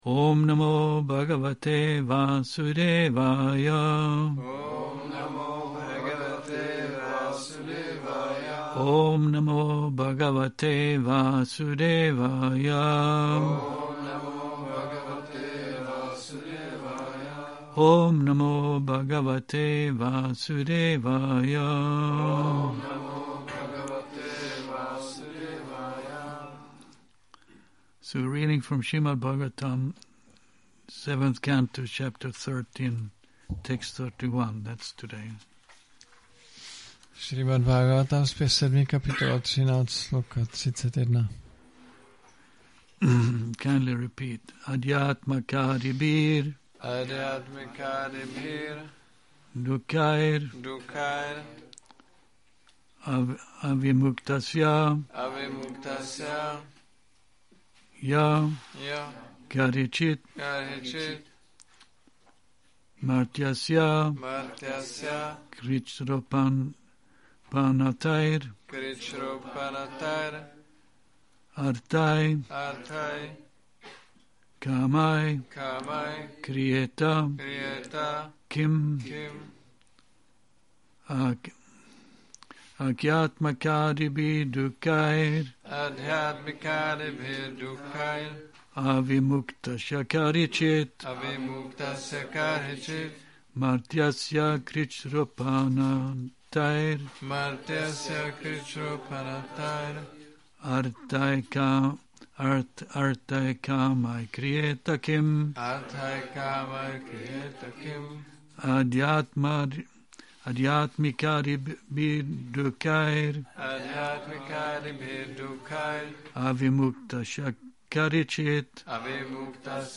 Šrí Šrí Nitái Navadvípačandra mandir
Přednáška SB-7.13.31